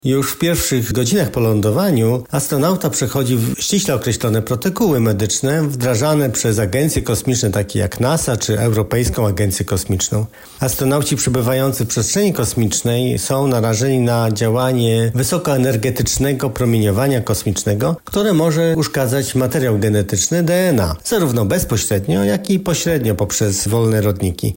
nefrolog i specjalista chorób wewnętrznych na temat badań po powrocie z kosmosu.